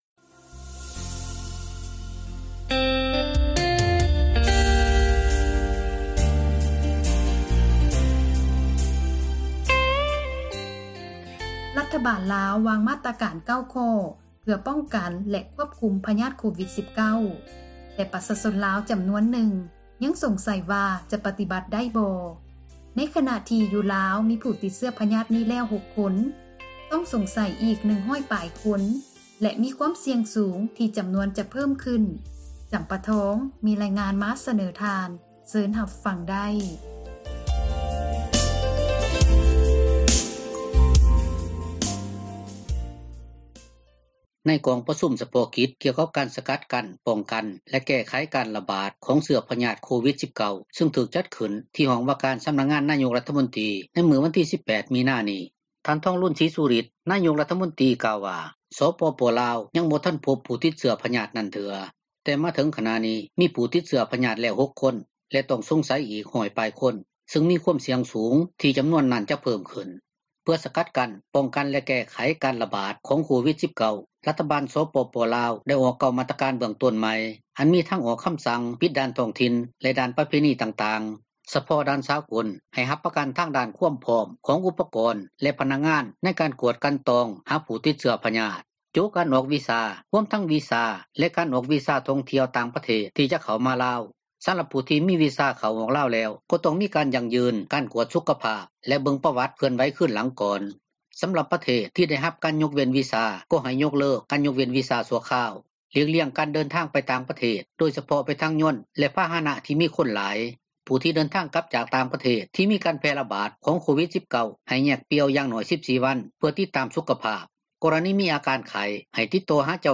ມີຣາຍງານ ມາສເນີທ່ານ ເຊີນຮັບຟັງໄດ້...